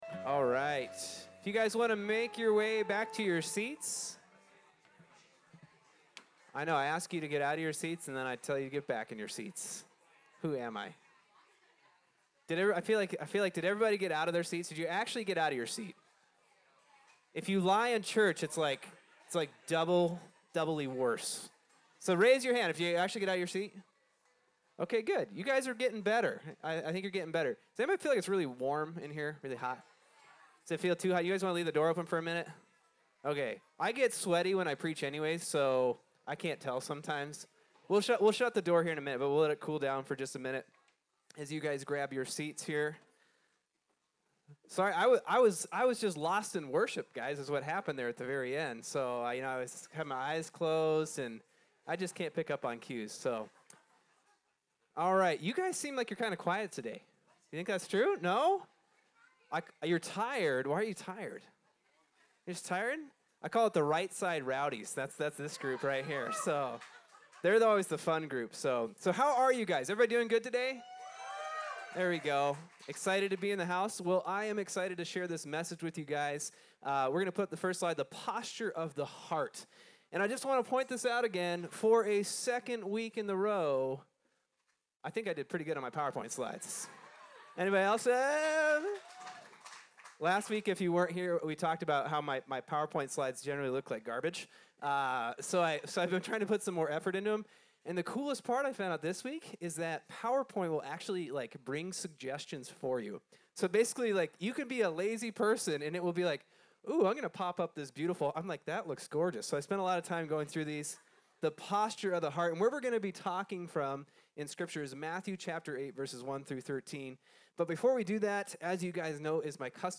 Bible Text: Mathew 8:1-13 | Preacher